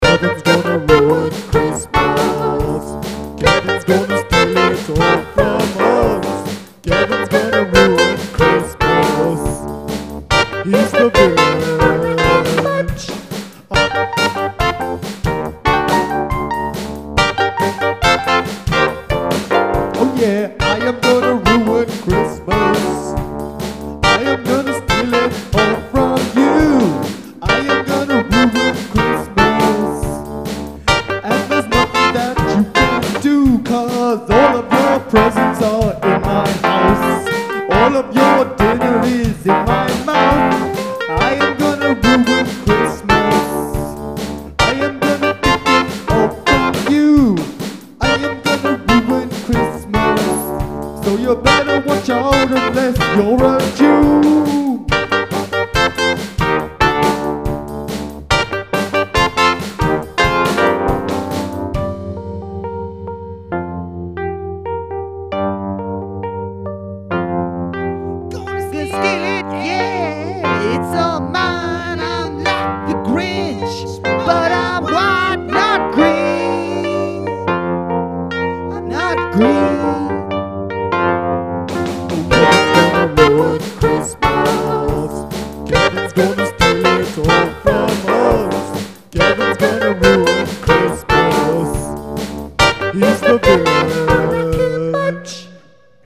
I am a pretty bad singer at the best of times, and tonight I was told that I sounded like a villain in an xmas film.
Cept I am not that good.